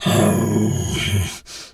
pgs/Assets/Audio/Animal_Impersonations/bear_roar_soft_11.wav at master
bear_roar_soft_11.wav